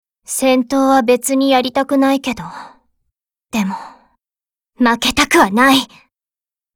Cv-30210_warcry.mp3